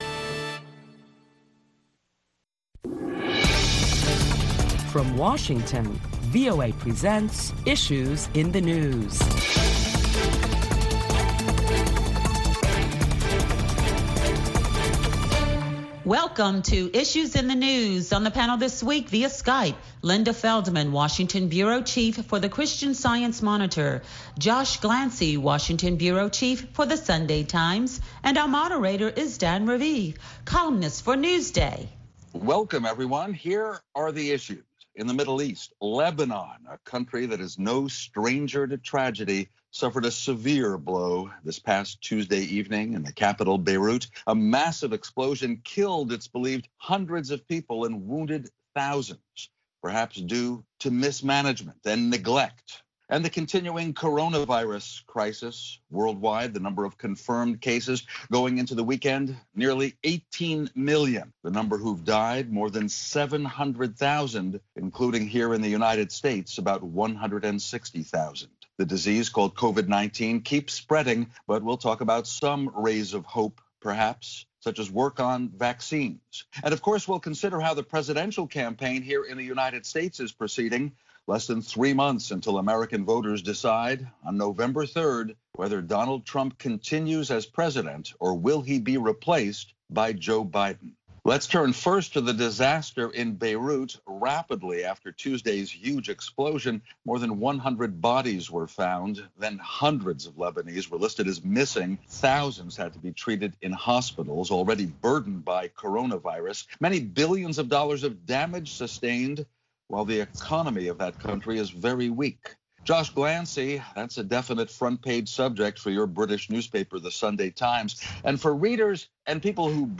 Join a panel of prominent Washington journalists as they deliberate the latest top stories of the week which includes the topic of the U.S. approaching 5 million confirmed cases of the coronavirus, far outpacing other countries.